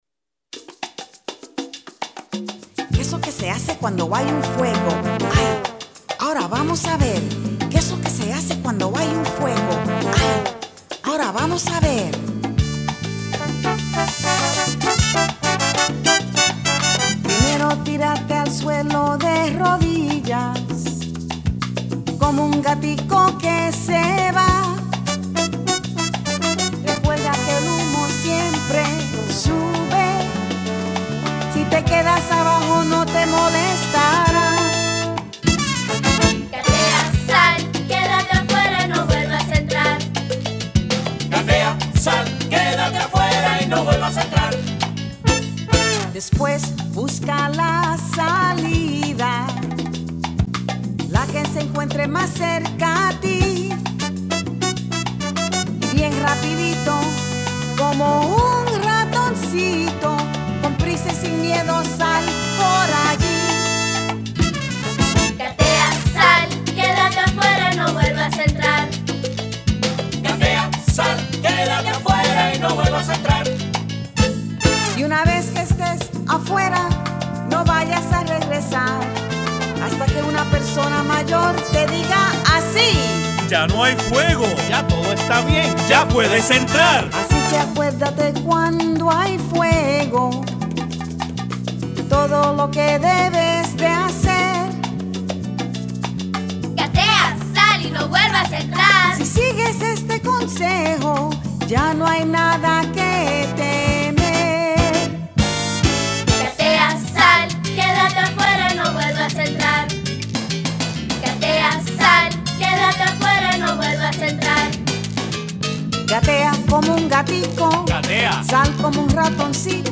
Songs and stories